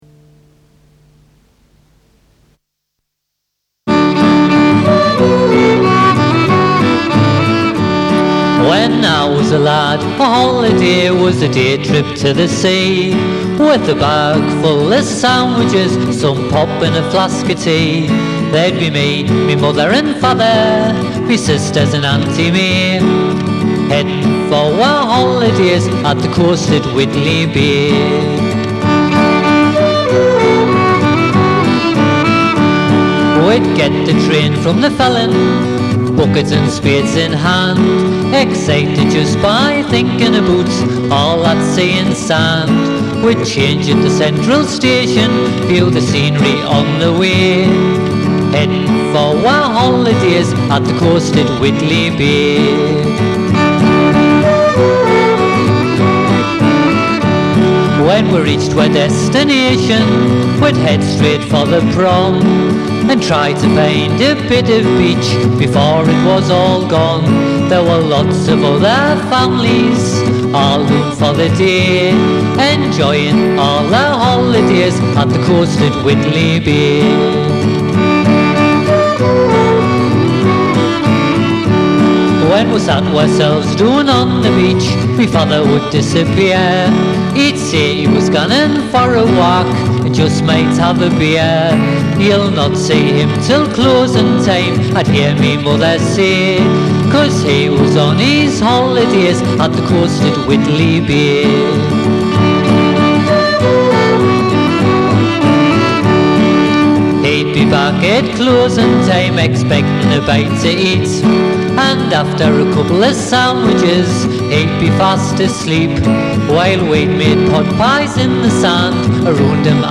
Vocals & Guitar
Violin
Flute